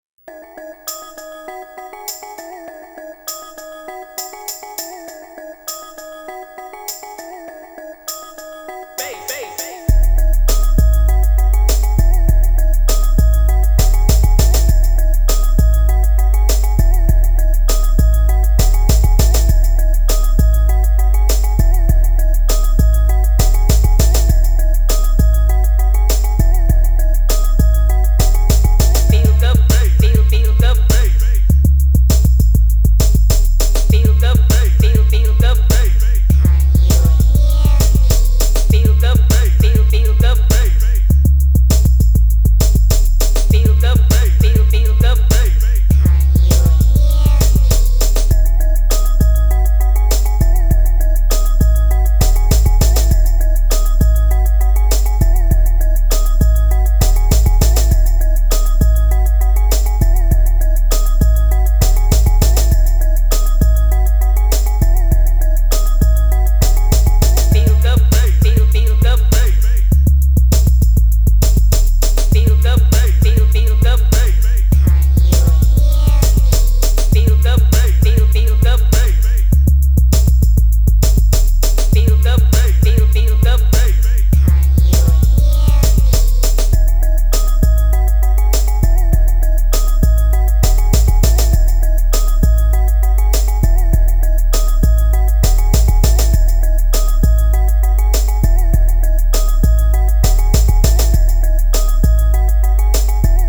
BASS___SubWoofer_Test___Big_Bass.mp3